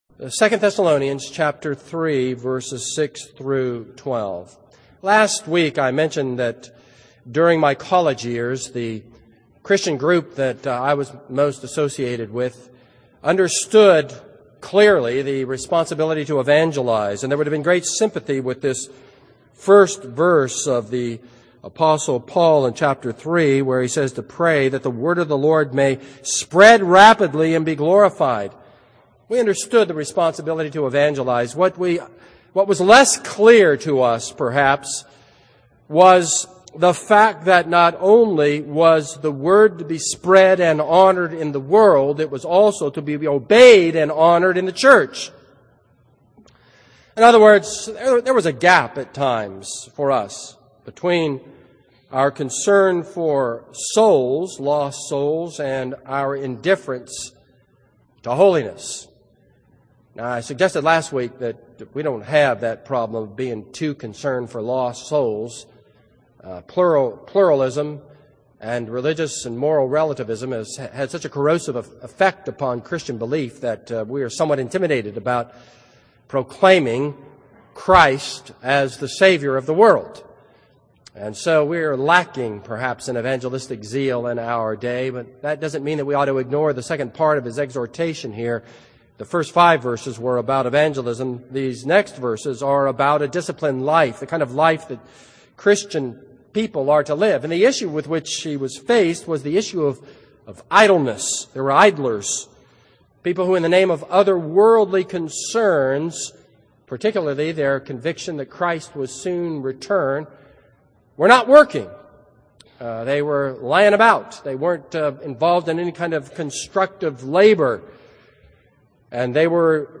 This is a sermon on 2 Thessalonians 3:8-12.